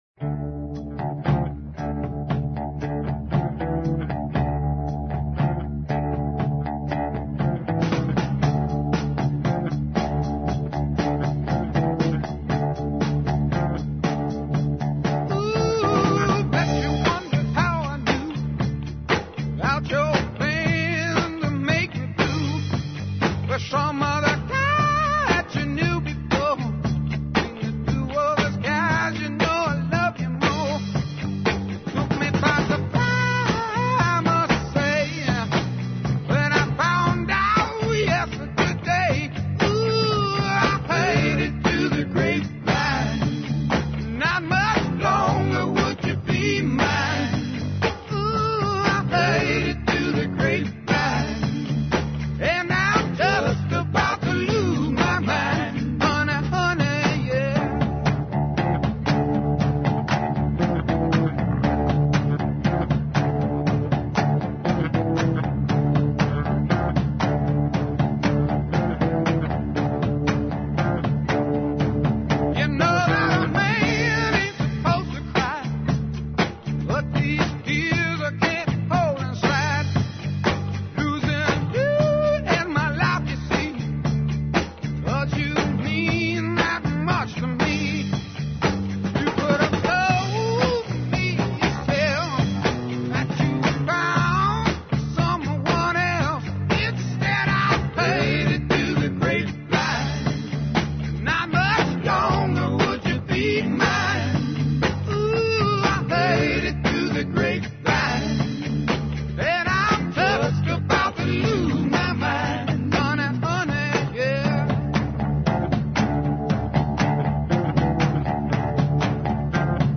Гост у другом сату емисије је трубач